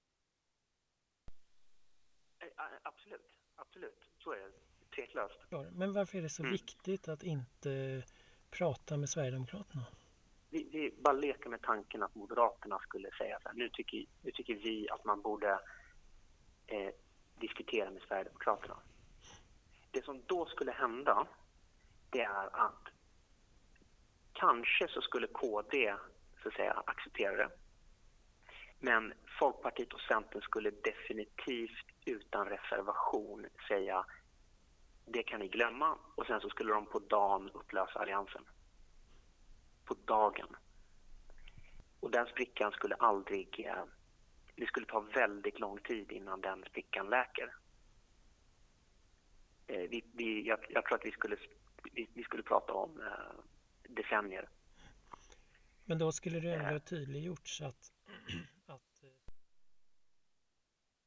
I Granskning Sveriges utfrågning av Fredrik Schulte säger han två saker som borde få många att reagera.